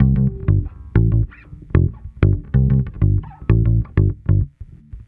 Track 13 - Bass 05.wav